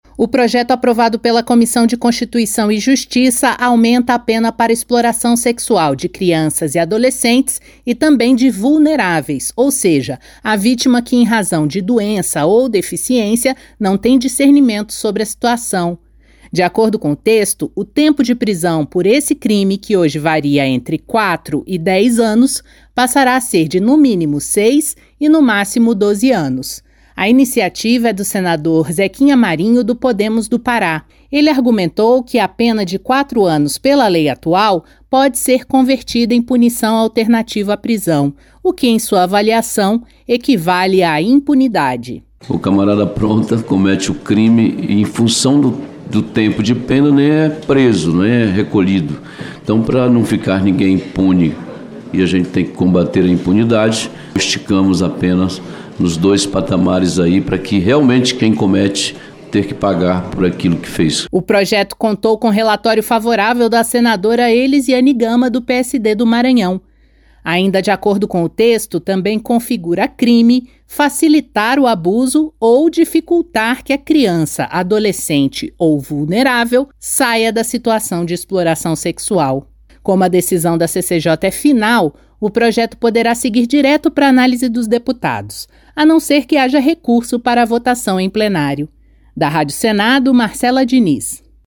Senador Zequinha Marinho